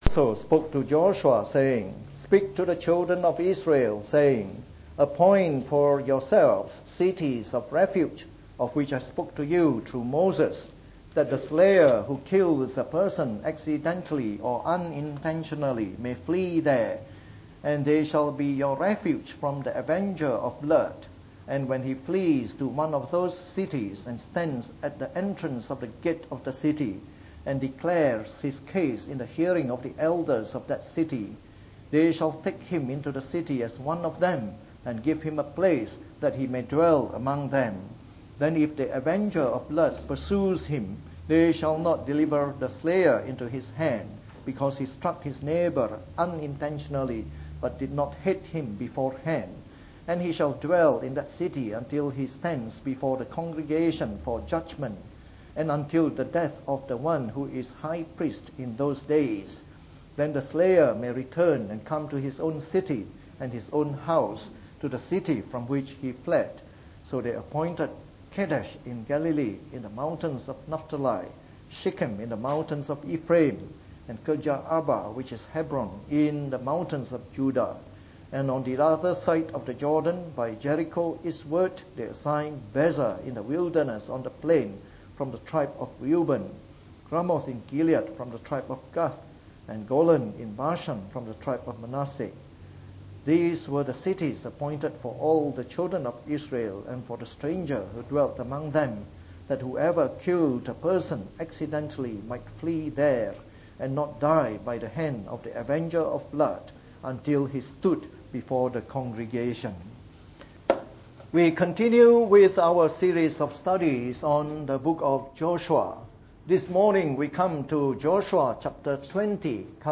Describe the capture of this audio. From our series on the Book of Joshua delivered in the Morning Service.